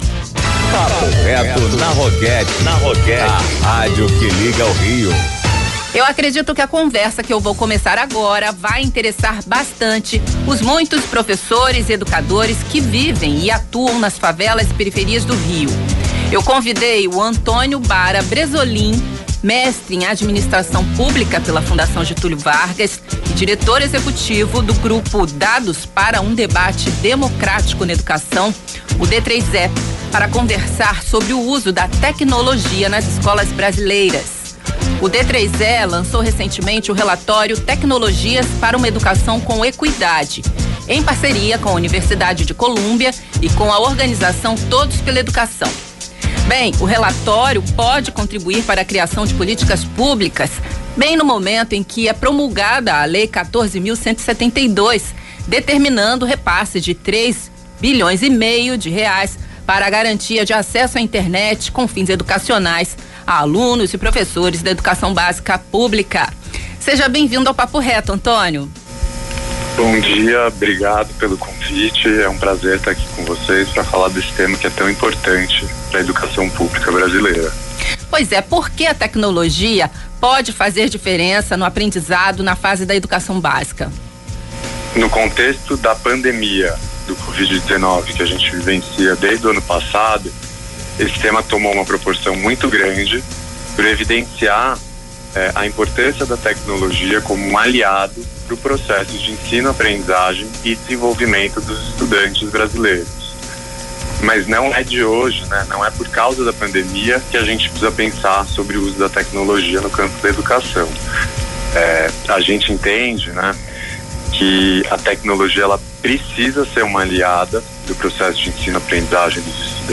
Entrevista
para a Rádio Roquette Pinto, do Rio de Janeiro